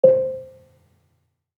Gambang-C4-f.wav